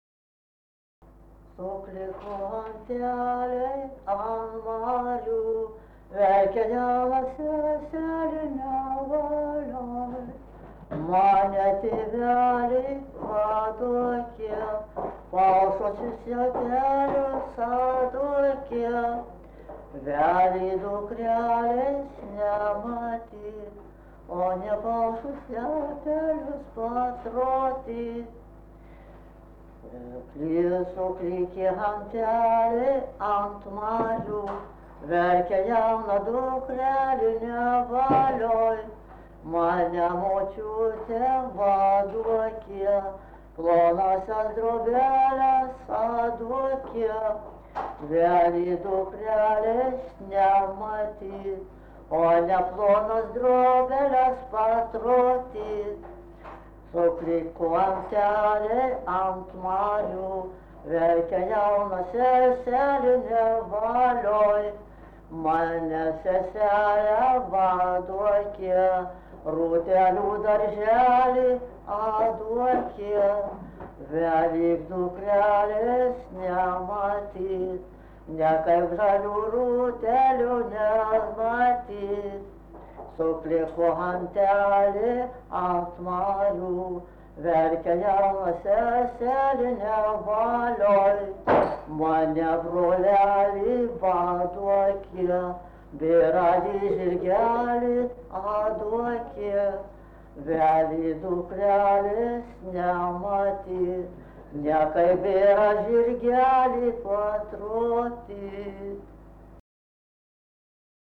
vaišių daina
Atlikimo pubūdis vokalinis